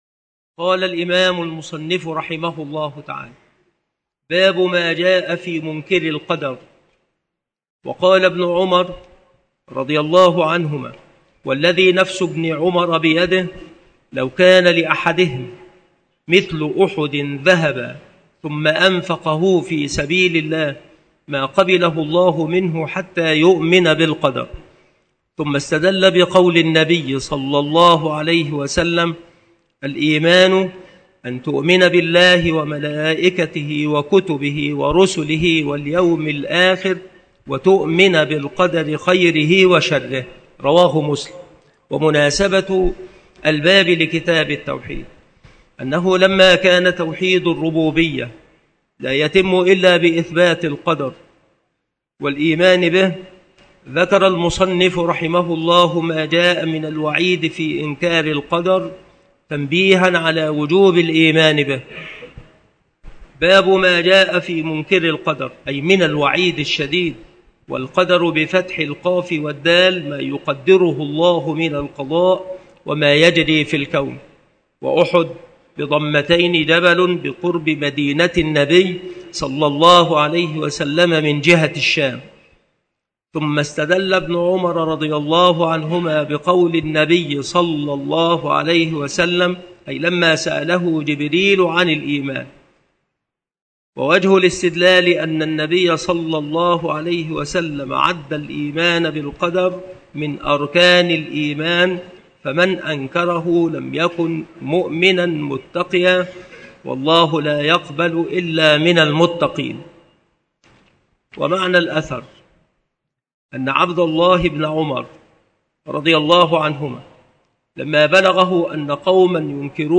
مكان إلقاء هذه المحاضرة بالمسجد الشرقي بسبك الأحد - أشمون - محافظة المنوفية - مصر عناصر المحاضرة : مناسبة الباب لكتاب التوحيد. حكم منكري القدر. مراتب الإيمان بالقدر. من ثمرات الإيمان بالقضاء والقدر.